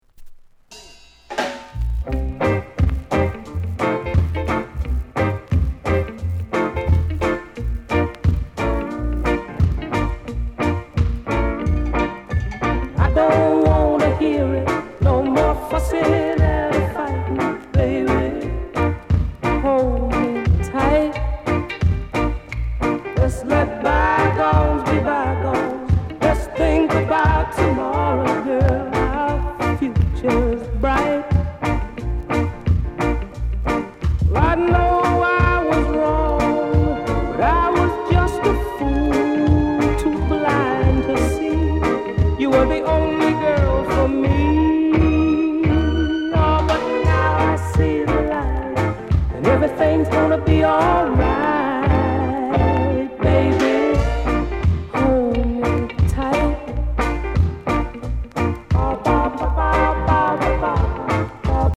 SOUND CONDITION VG
ROCKSTEADY